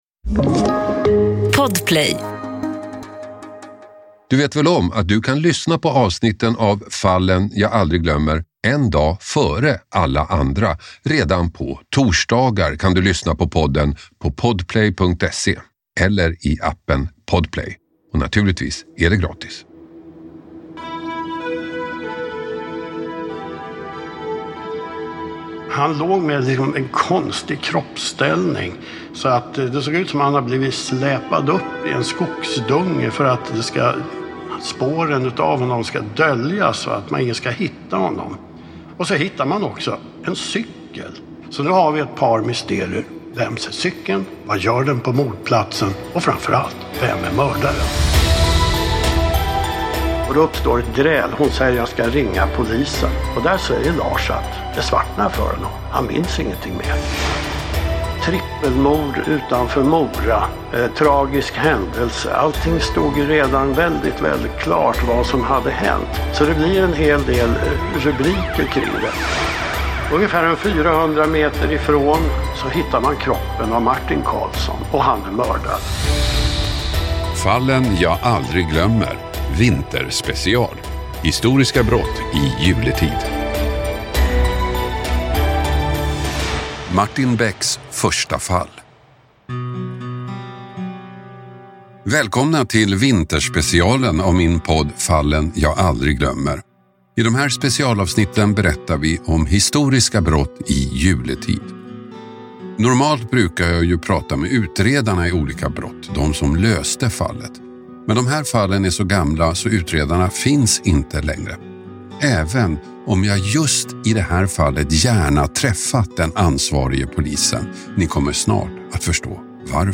Hasse Aro får sällskap i studion av kriminalhistorikern och författaren